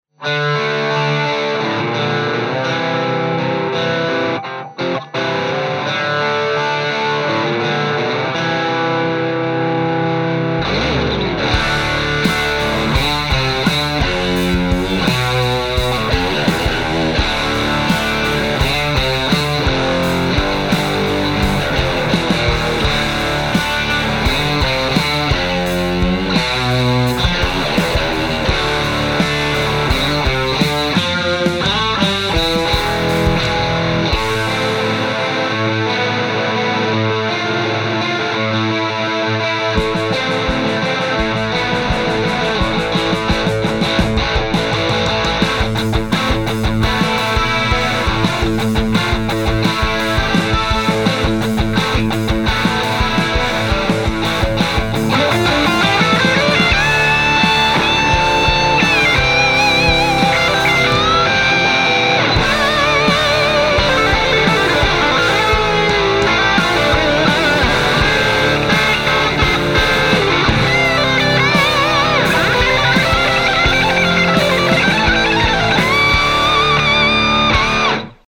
The PlexiVibe does everything from late 60`s bluesy inspired plexi tones and goes through to more modern, modded plexi sounds.
Plexi-Vibe-Boost.mp3